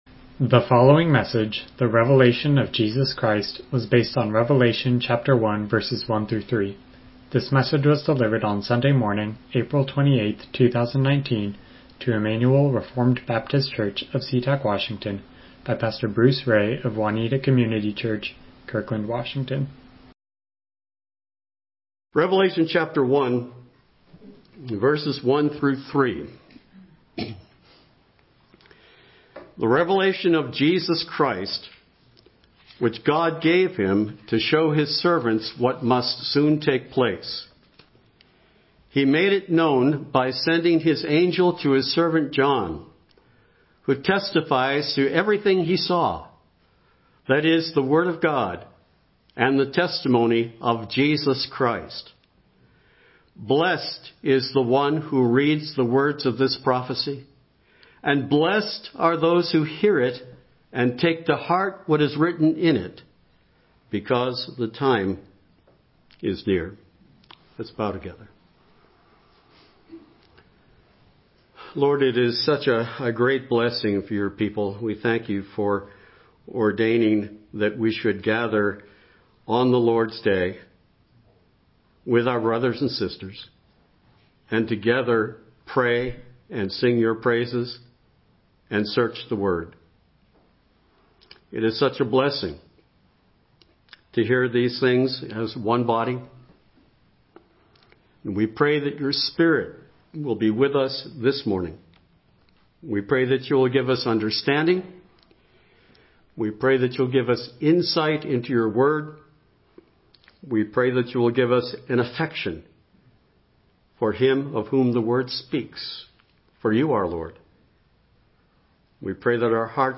Passage: Revelation 1:1-3 Service Type: Morning Worship